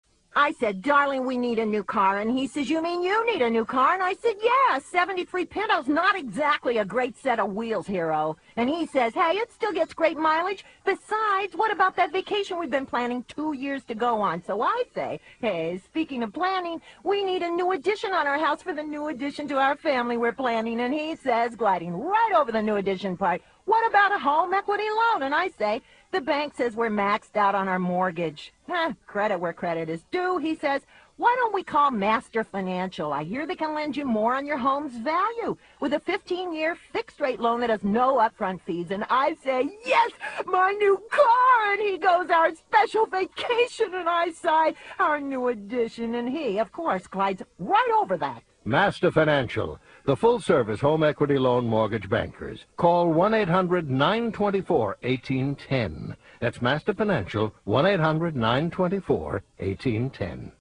Various Radio Commercial Work